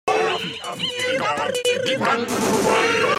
دانلود آهنگ رادیو 1 از افکت صوتی اشیاء
جلوه های صوتی